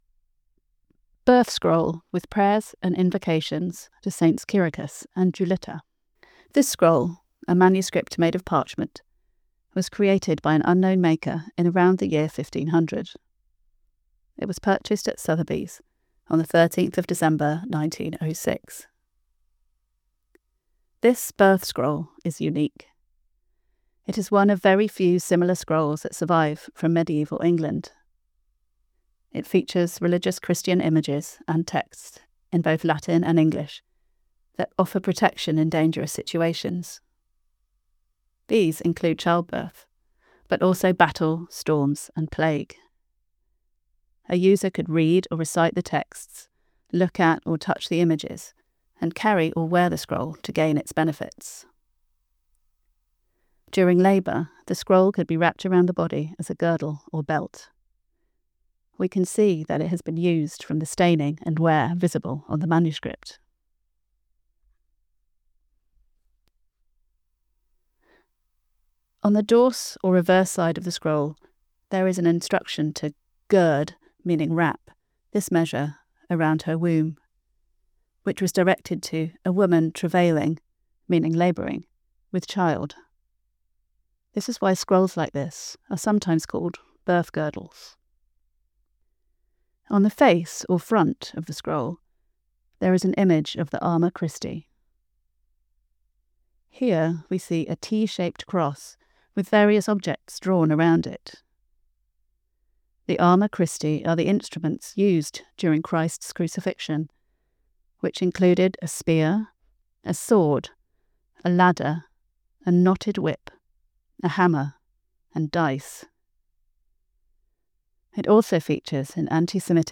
Audio description of the medieval birth scroll